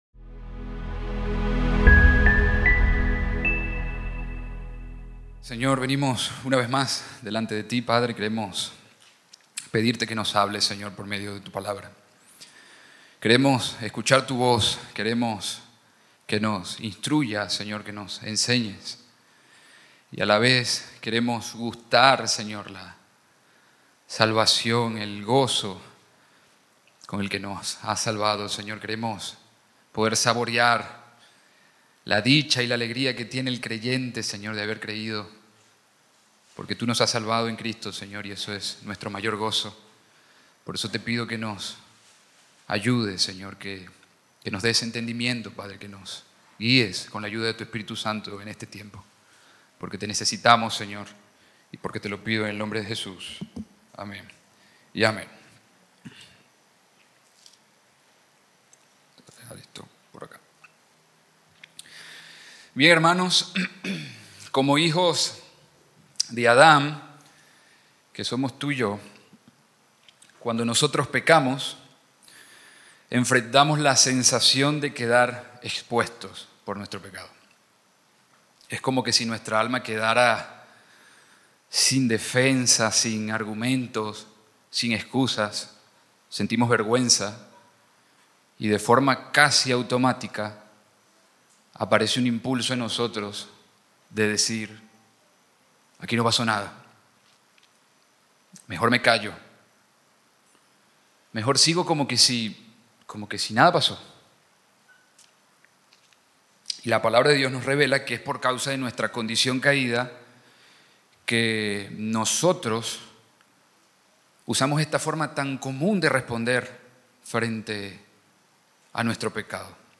Sermón 4 de 8 en Delante de Dios